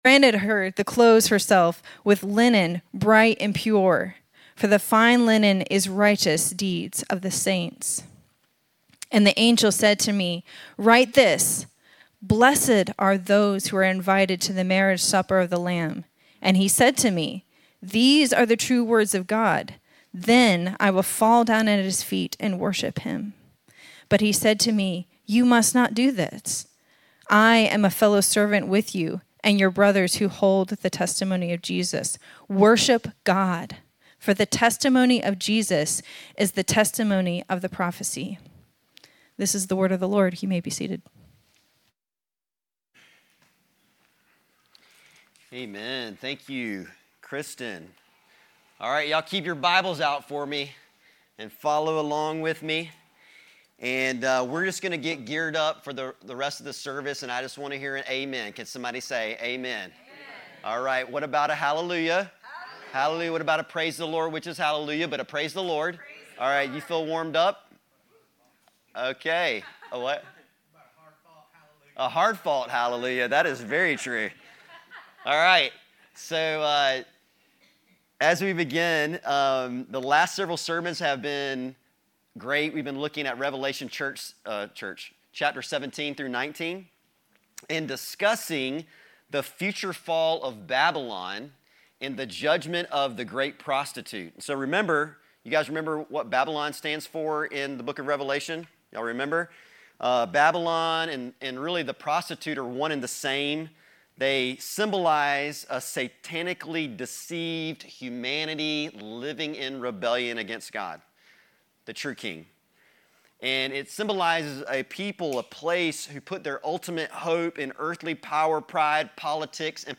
Sermons - Connection Fellowship